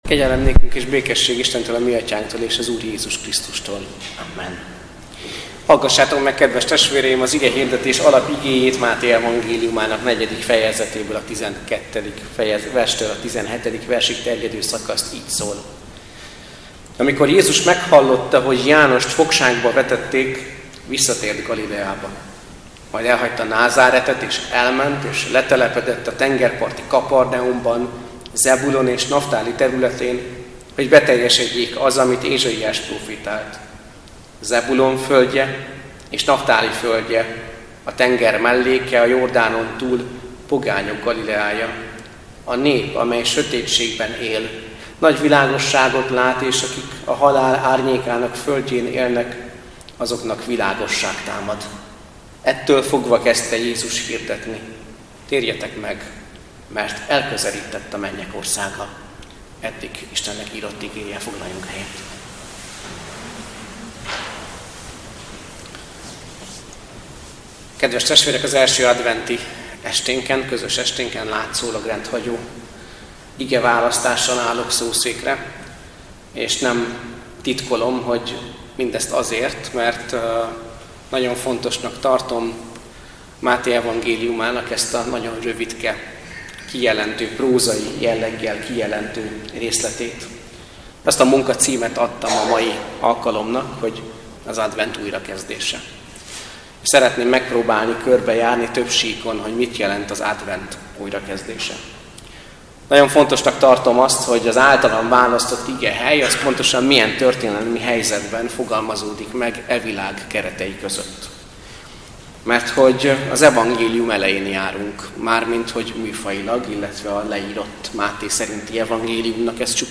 Advent esti áhítat - Csak szeressétek az igazságot és a békességet! (Zak 8,19b)